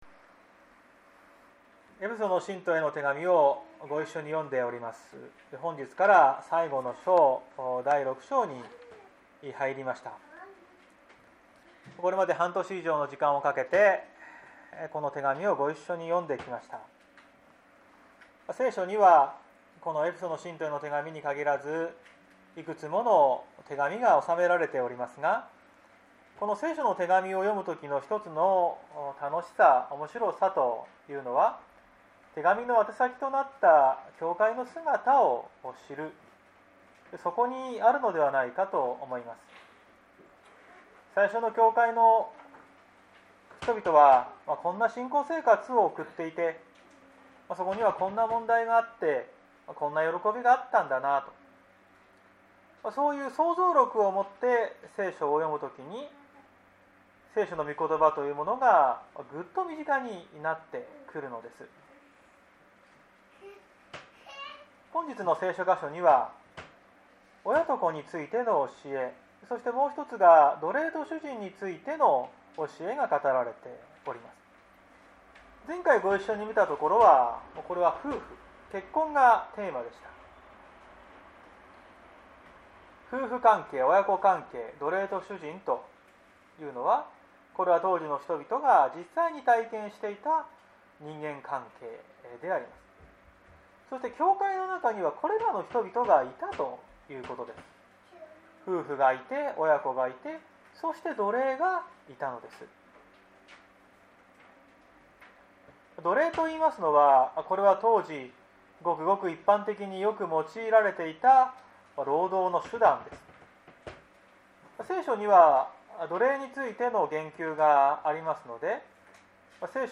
2021年04月18日朝の礼拝「主に結ばれる者として」綱島教会
説教アーカイブ。